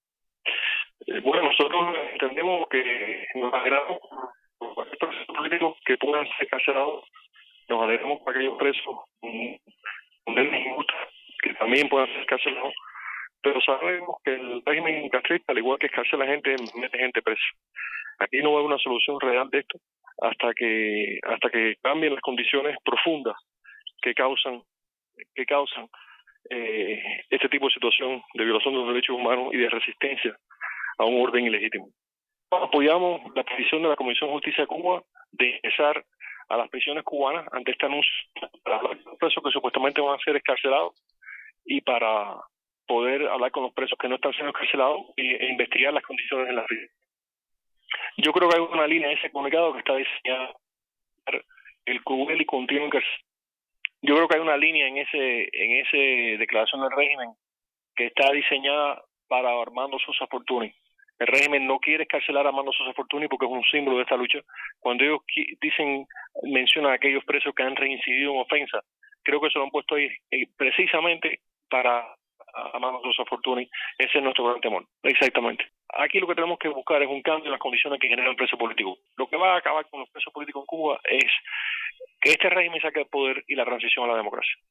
En entrevista con Radio Televisión Martí